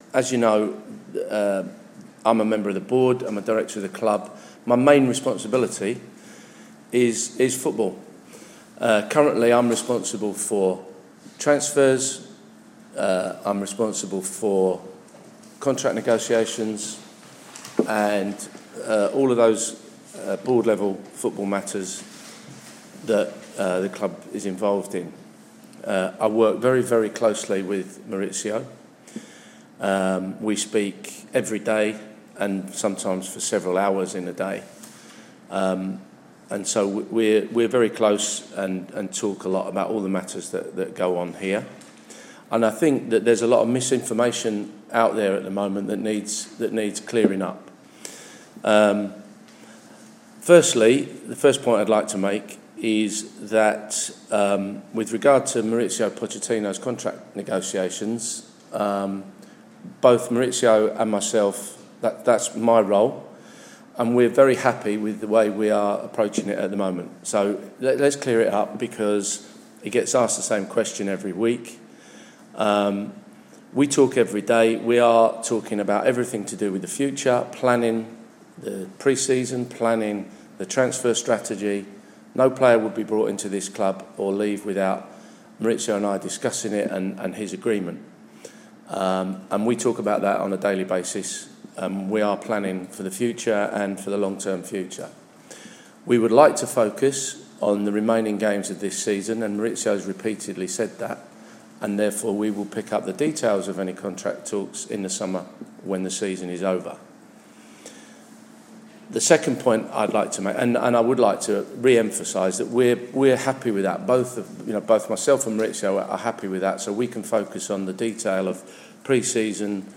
LISTEN The full statement